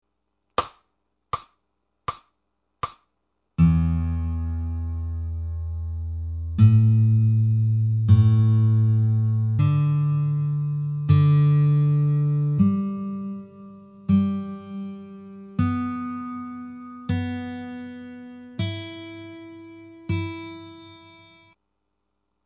1-How-To-Tune-Backing-Track-Reference-Tones-.mp3